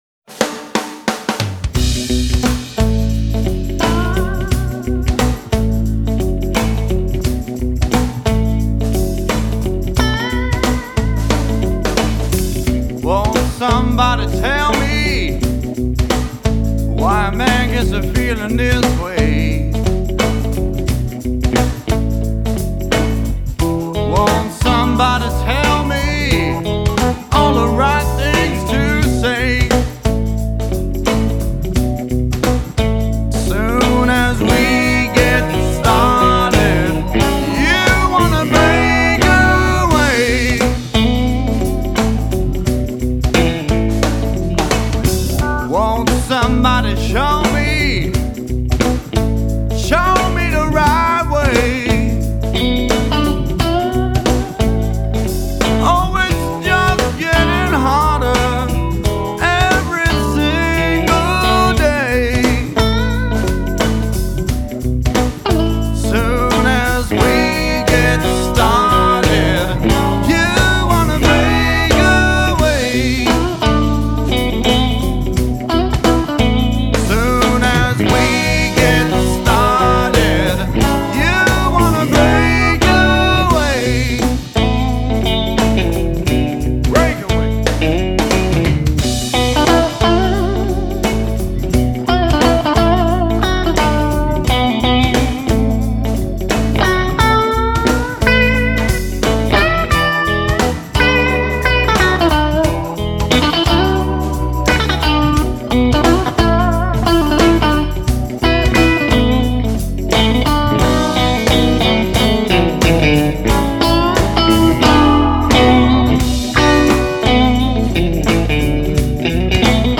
offers up a gutsy, yet classy sound